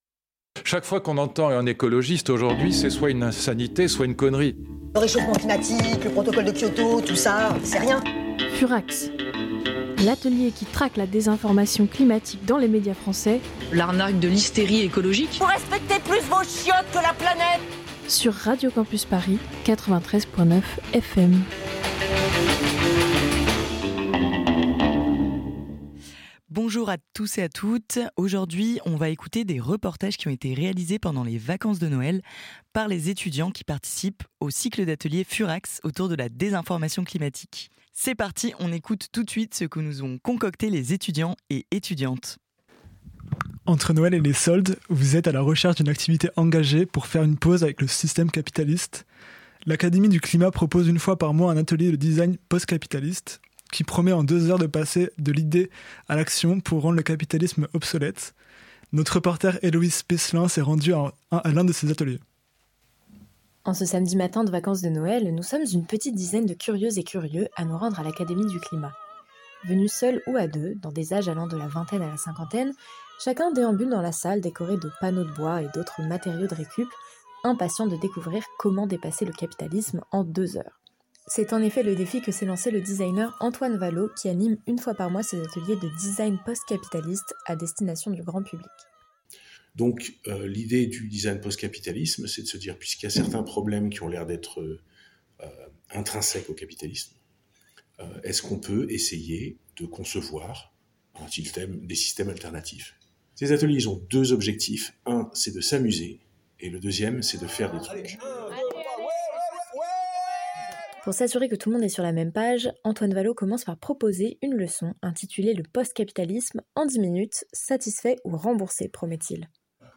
A l'occasion des vacances d'hiver, les étudiant·es de l'atelier FURAX se sont rendu·es sur le terrain avec leurs micros. Une seule contrainte : parler d'écologie.
D'un bar associatif lillois jusqu'à la brocante d'une paroisse en passant par un marché de livres anciens et un atelier de design post capitaliste, les fêtes de fin d'année ont été bien remplies.